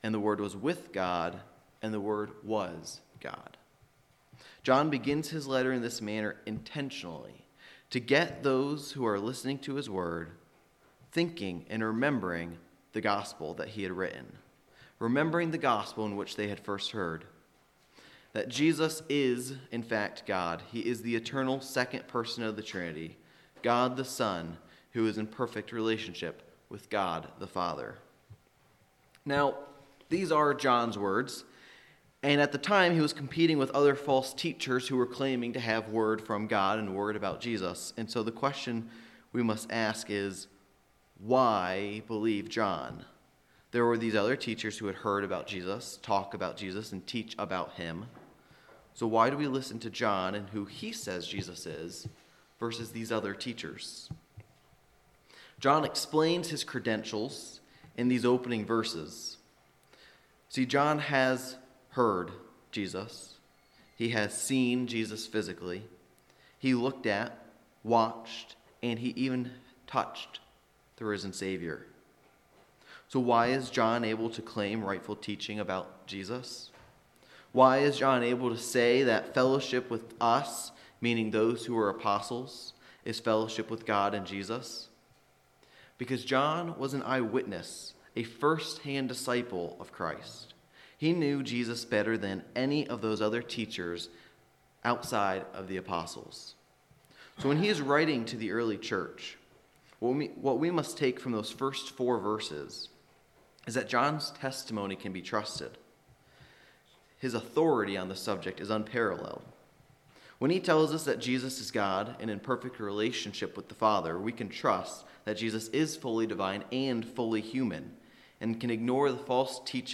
Worship Service – June 16, 2024 « Franklin Hill Presbyterian Church